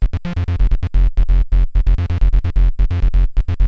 SONS ET LOOPS GRATUITS DE BASSES DANCE MUSIC 130bpm
Basse dance 2 E